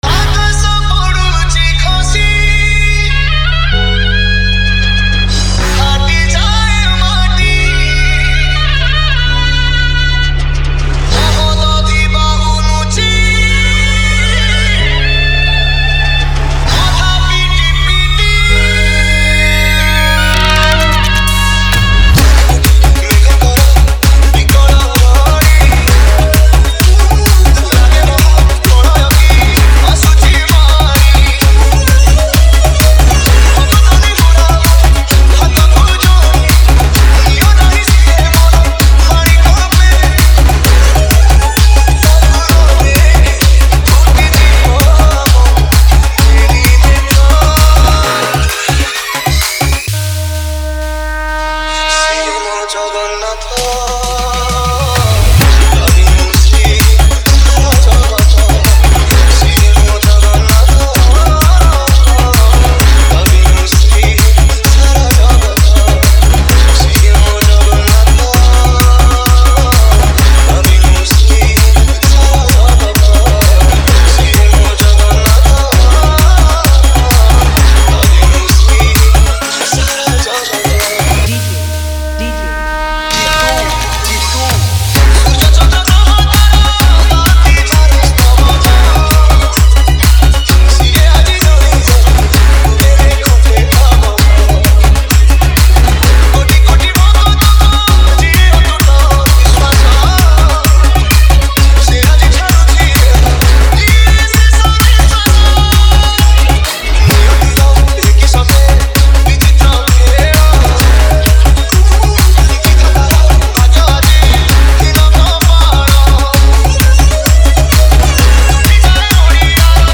Bhajan Dj Song Collection 2023